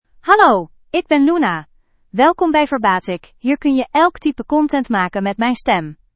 FemaleDutch (Netherlands)
Luna — Female Dutch AI voice
Voice sample
Listen to Luna's female Dutch voice.
Luna delivers clear pronunciation with authentic Netherlands Dutch intonation, making your content sound professionally produced.